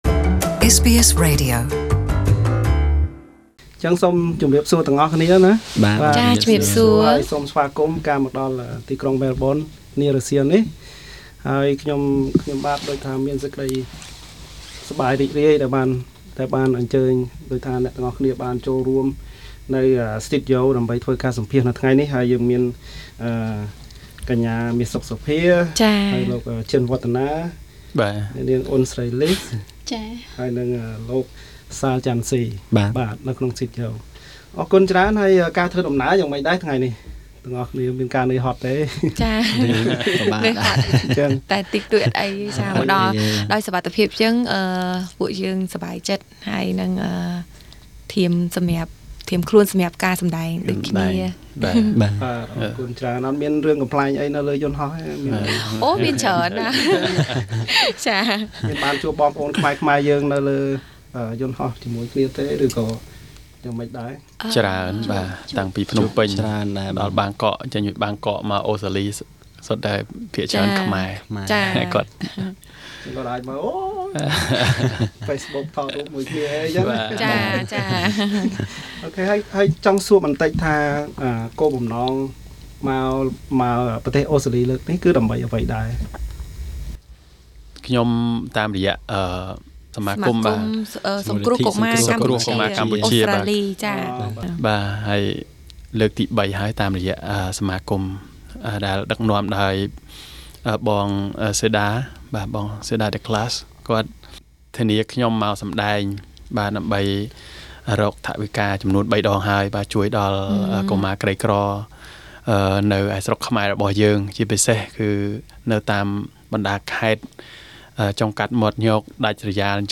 Pop Star Meas Sok Sophes is in Australia with other 3 entertainers to join the SCCFA charity concerts for raising funds to educate poor kids in Cambodia. Here is their interview with SBS Khmer.